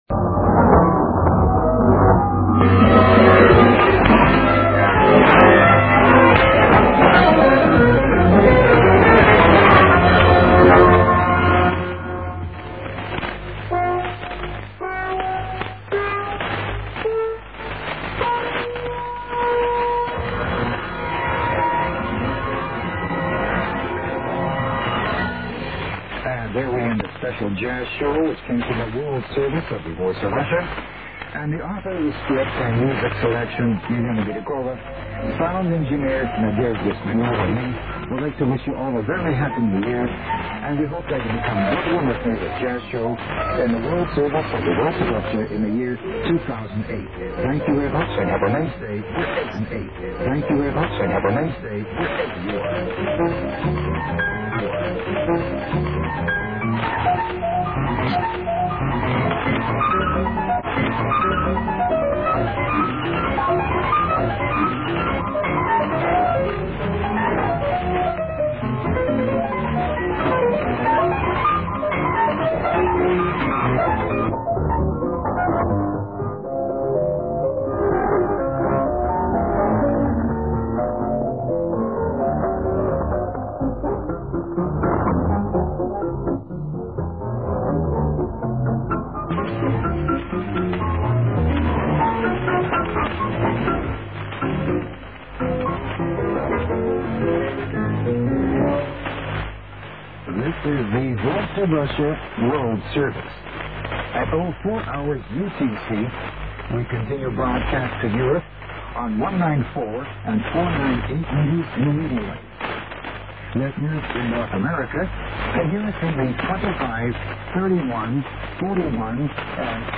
DXPedition Itatiaiuçu-MG JAN-2008
ICOM IC-R75 c/DSP + Ham Radio Deluxe
02 Antenas Super KAZ 90 graus uma da outra NORTE-SUL E LESTE-OESTE